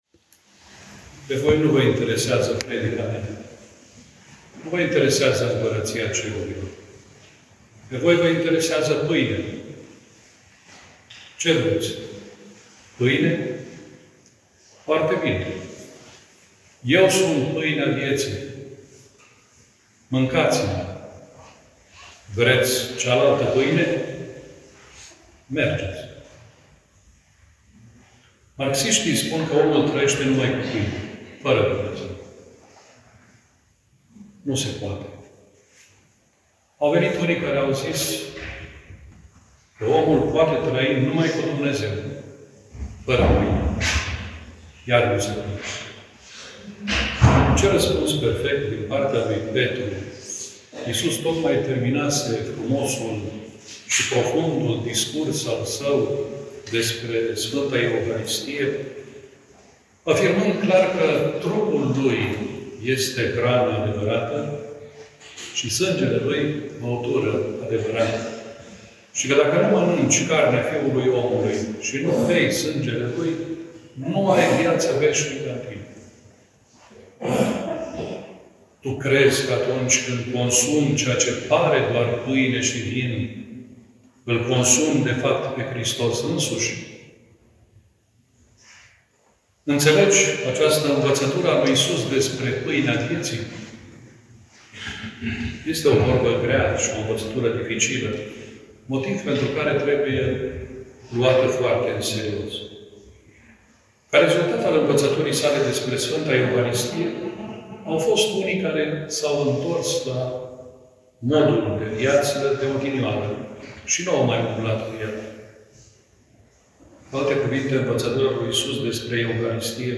Iași: Întâlnirea națională de primăvară a terezinelor * 10 mai 2025 - Institutul Secular al Sfintei Tereza a Pruncului Isus"
�nregistrarea predicii: (descarc� mp3)